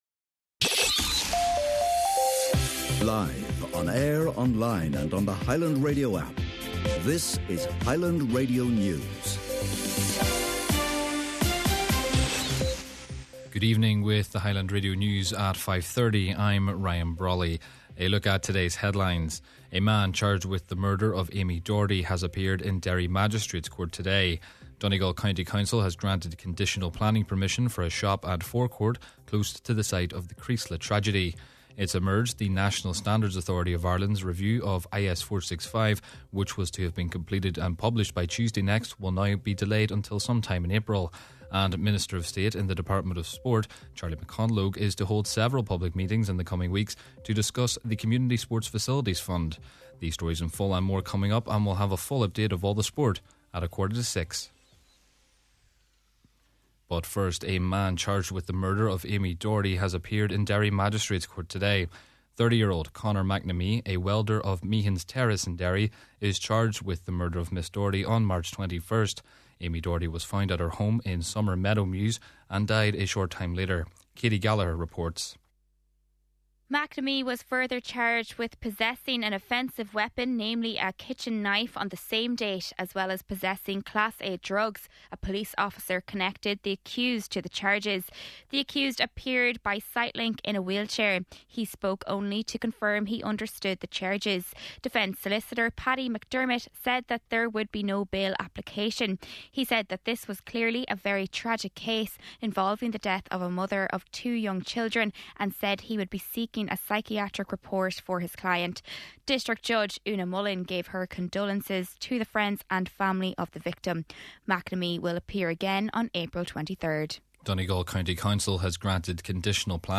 Main Evening News, Sport, An Nuacht and Obituary Notices – Friday, March 27th